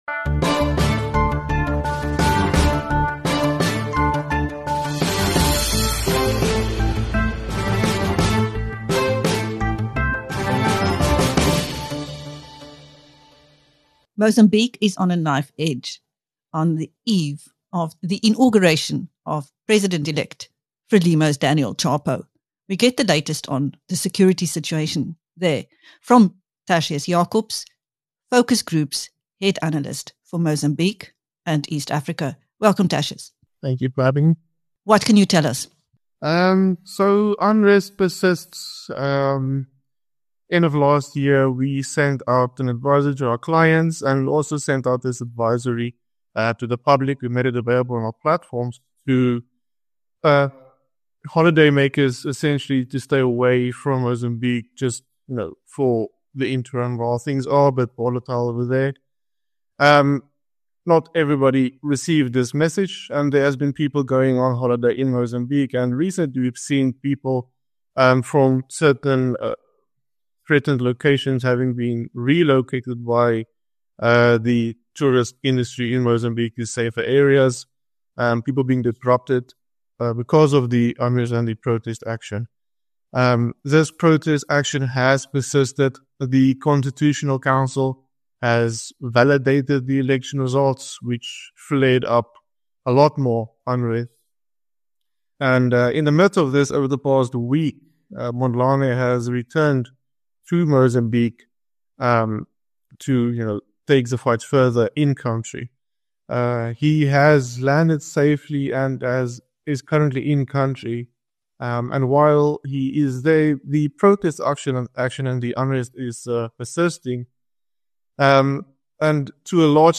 Mozambique is on a knife edge on the eve of the inauguration of President-elect, Frelimo’s Daniel Chapo. In this interview